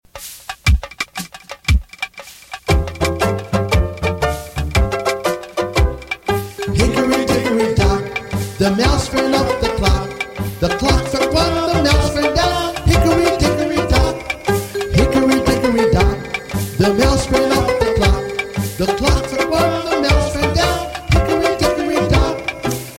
Children's Nursery Rhyme and Sound Clip